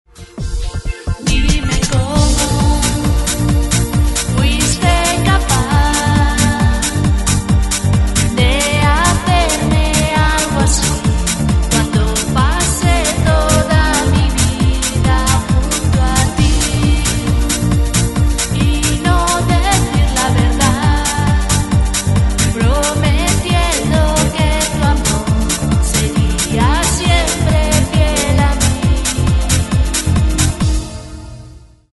producción épica